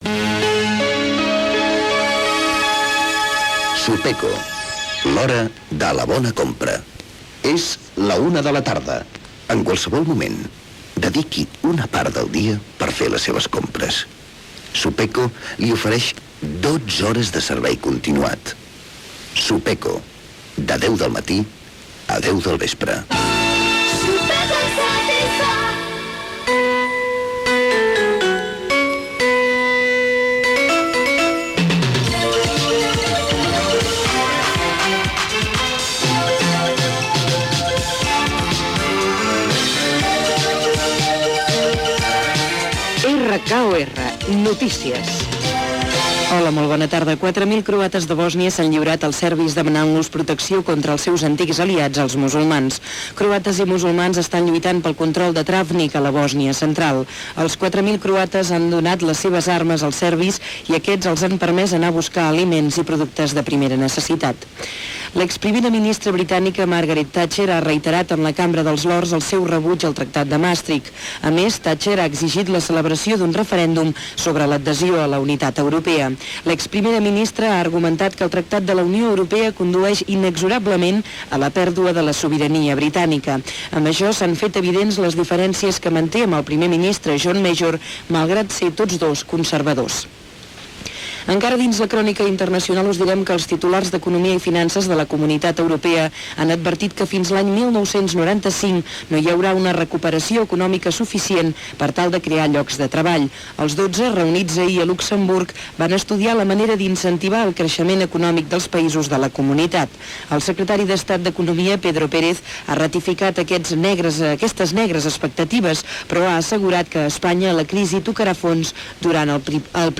Publicitat, hora, notícies: la guerra de Bòrnia, el Regne Unit i el Tractat de Maastricht, anàlisis dels partits polítics espanyols i catalans de les eleccions generals, el cas Filesa, el preu de la benzina, etc. Publicitat. Indicatiu de l'emissora
Informatiu